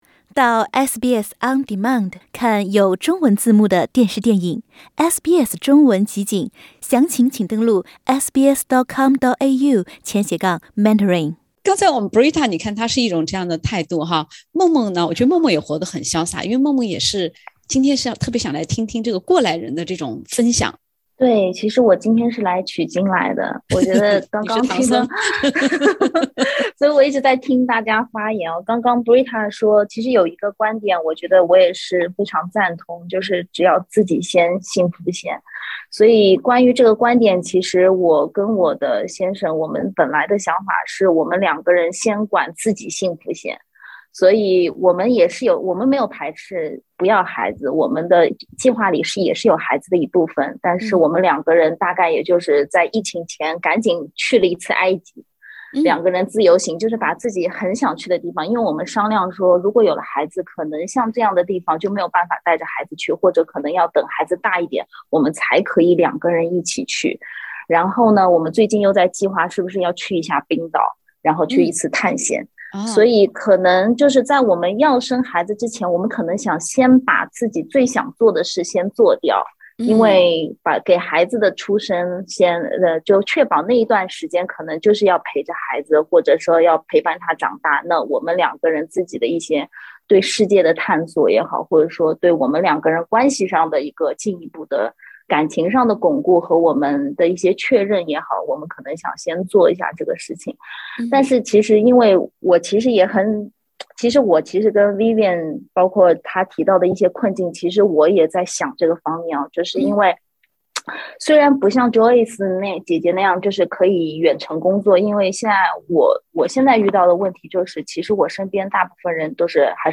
先顾家再立业，还是先立业再回归家庭。在考虑这个问题前我们是否应该更多考虑如何让自己的人生更丰满。（点击封面图片，收听轻松对话）